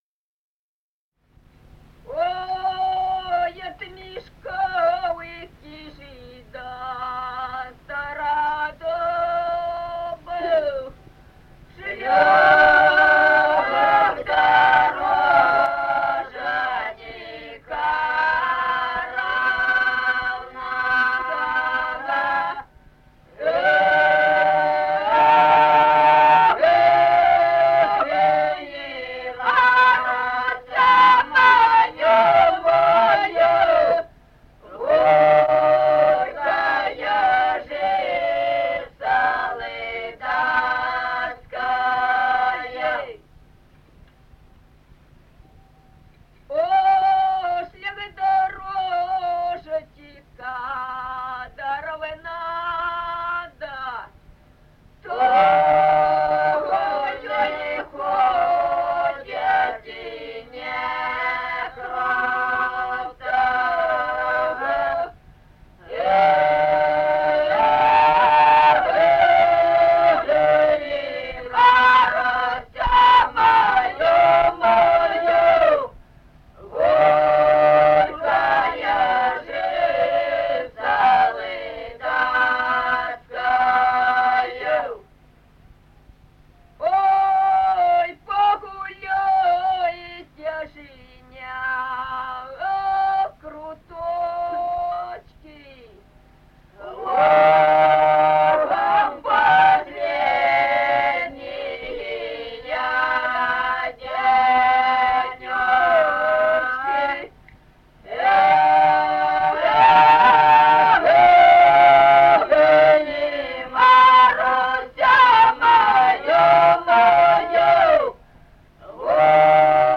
с. Мишковка.